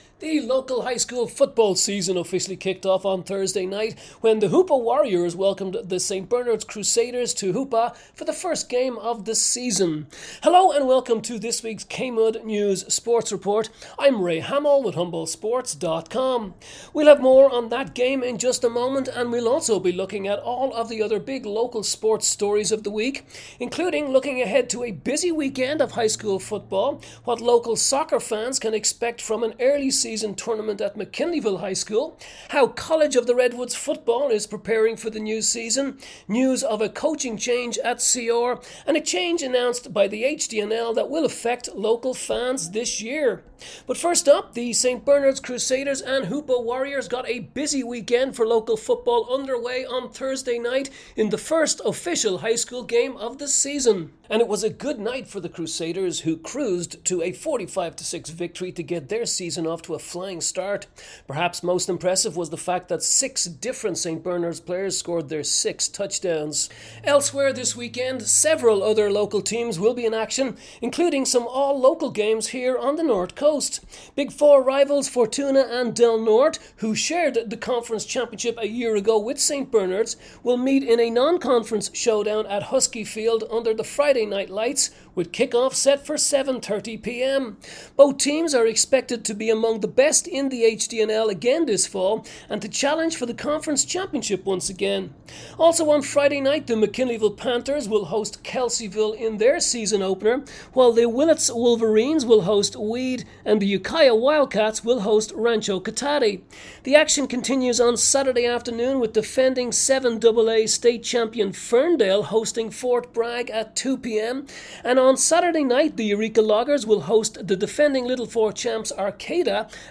Aug 30 KMUD Sports Report